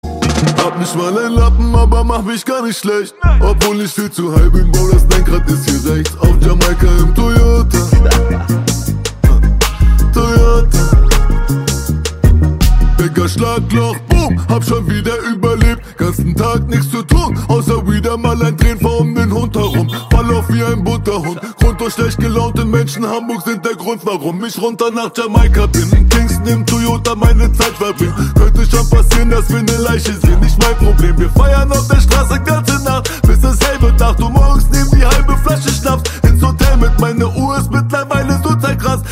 Kategorie Rap/Hip Hop